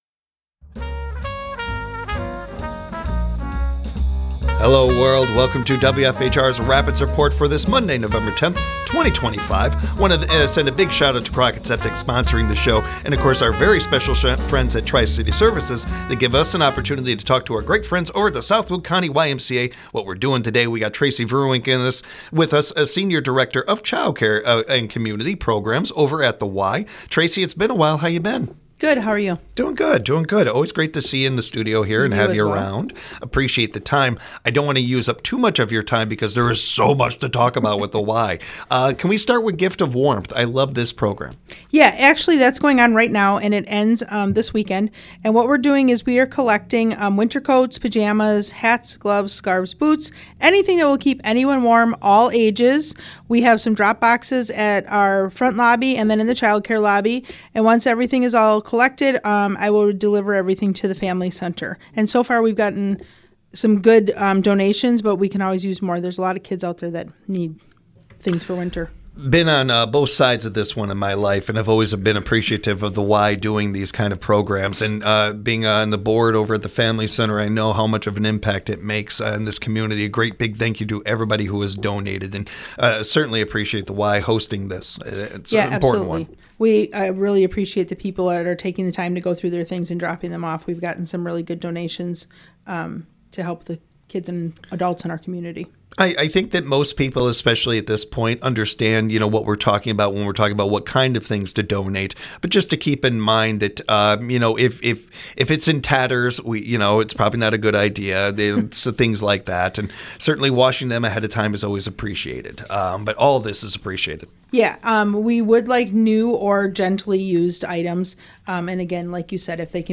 Special Thanks to Tri-City Services for sponsoring all SWC YMCA interviews!The Rapids Report is proudly sponsored by Crockett Septic.